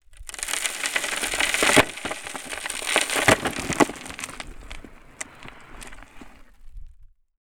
Sand_Pebbles_12.wav